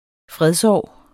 Udtale [ ˈfʁεðsˌɒˀ ]